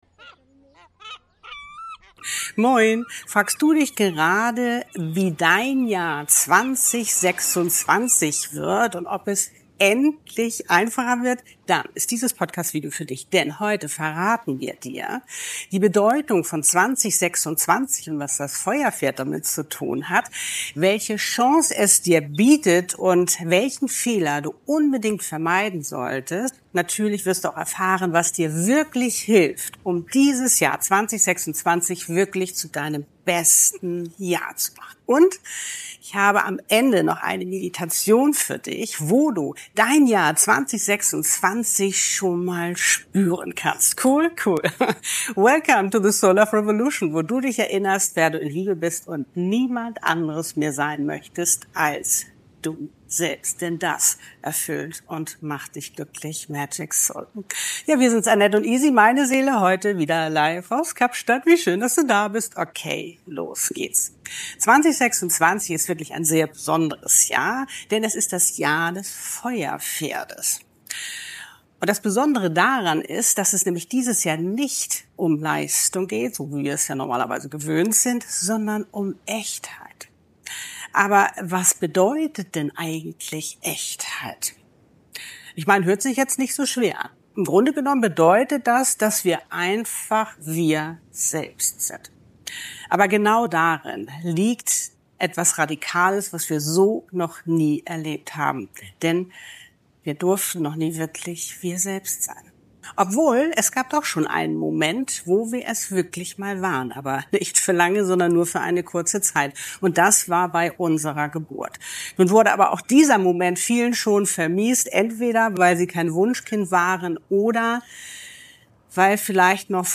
Und am Ende lade ich Dich zu einer kurzen Meditation ein, in der Du schon jetzt spüren kannst, wie sich Dein Jahr 2026 für Dich anfühlen möchte.